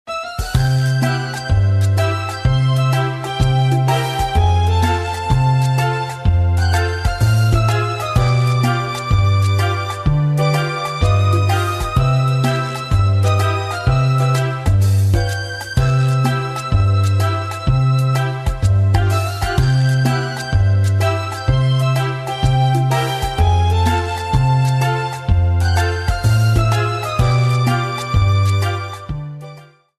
This is a sample from a copyrighted musical recording.